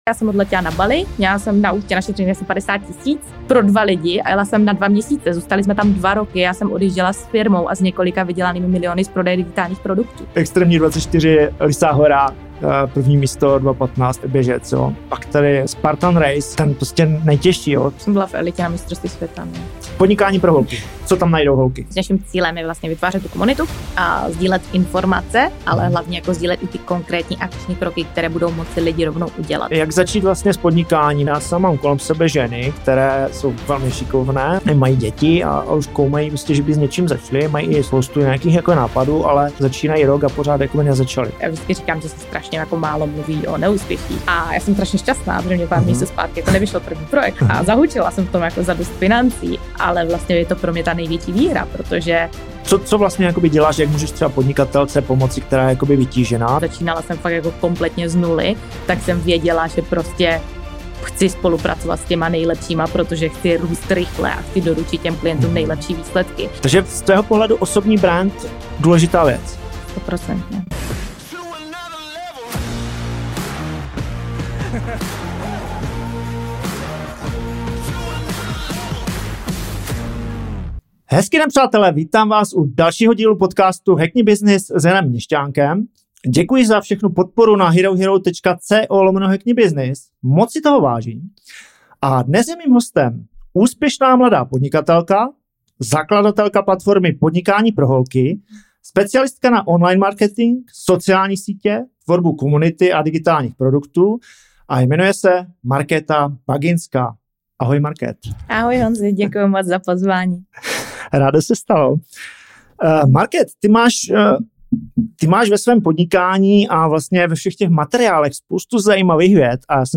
CELÝ ROZHOVOR